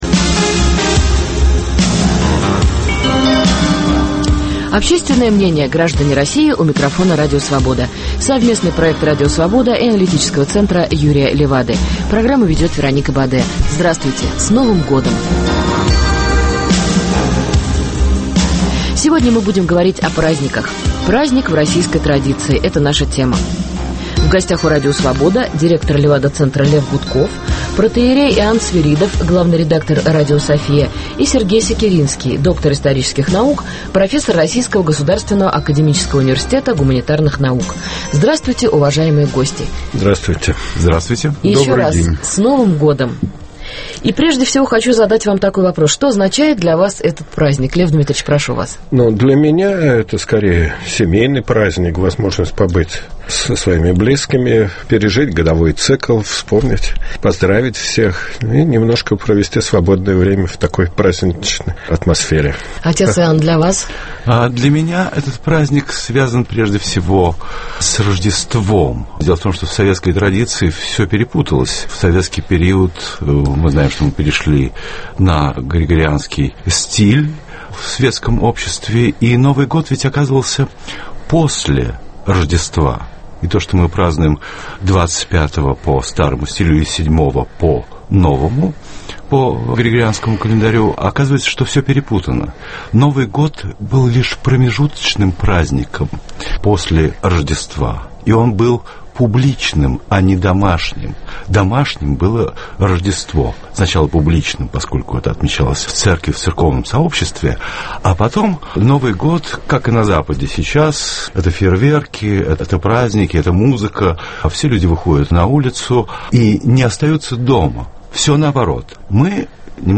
Праздник в российской традиции. Участники беседы